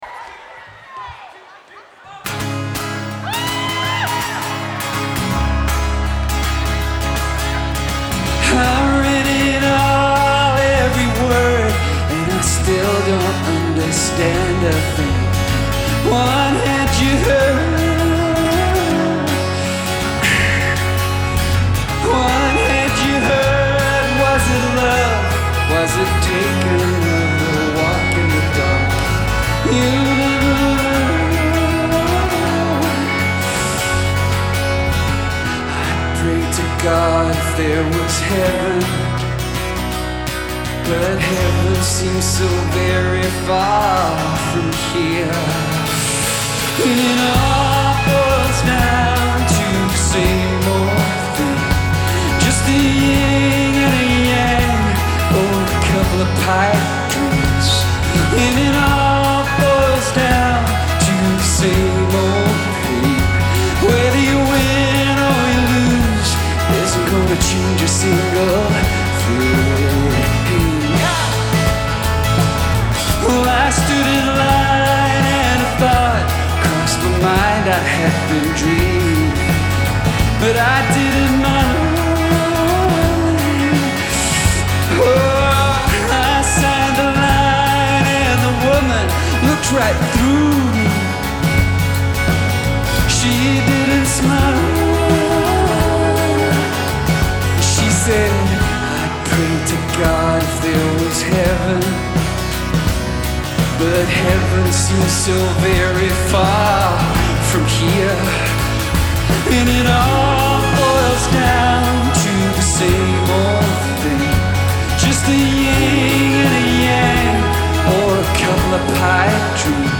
Genre : Alternatif et Indé